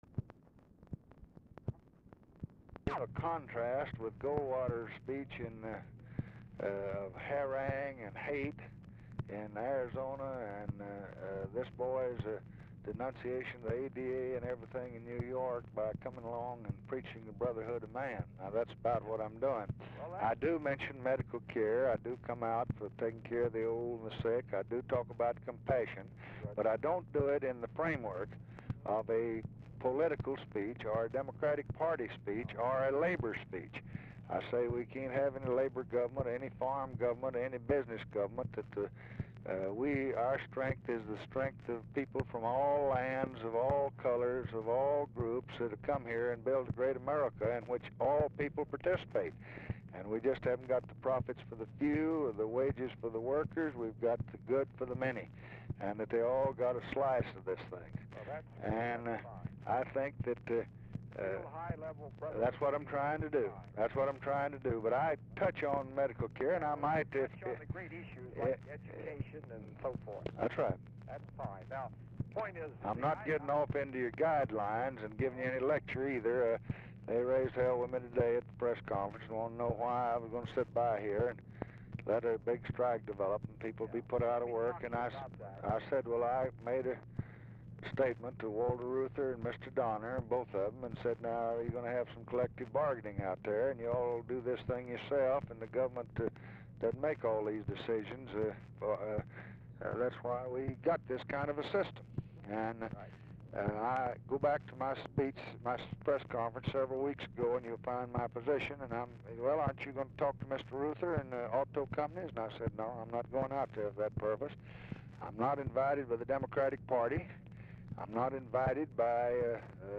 Telephone conversation
Dictation belt
Mansion, White House, Washington, DC